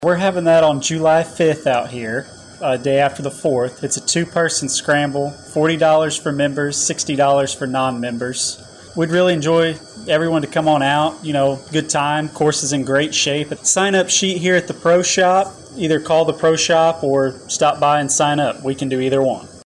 firecracker-golf.mp3